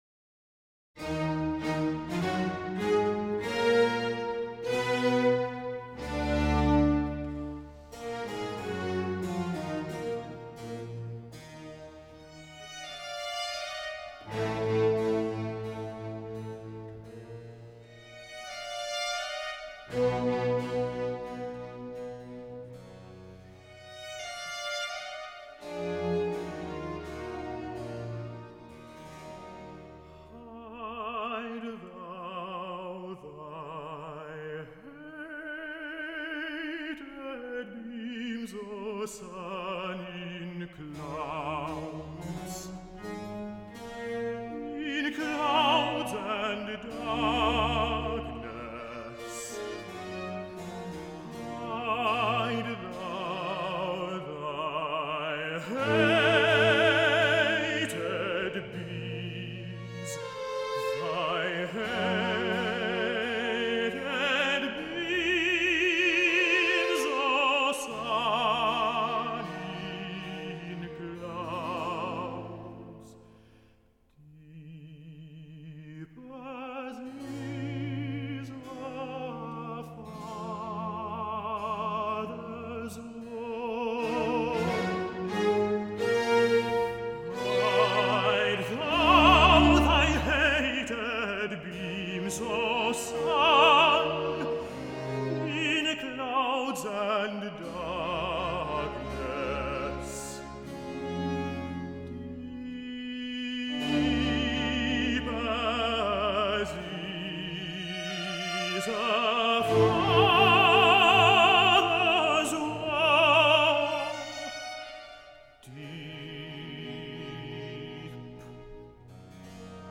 Arioso